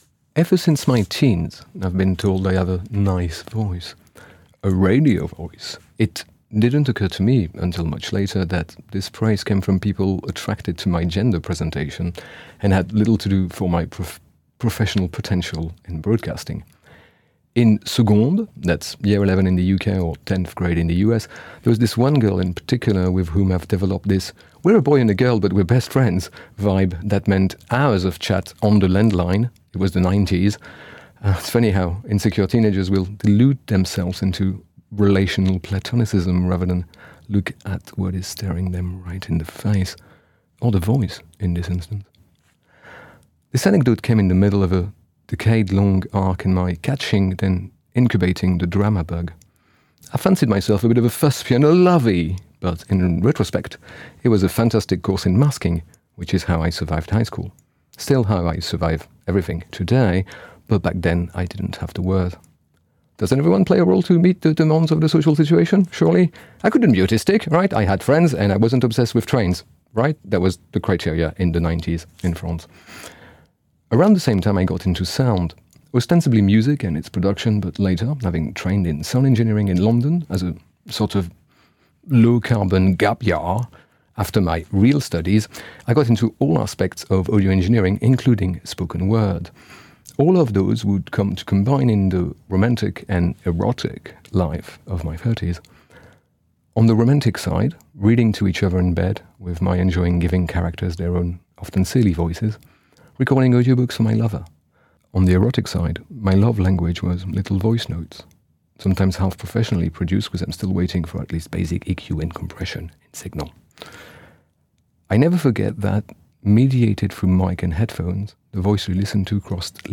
Point me to a pick with alt-text and I'll give it a saucy reading.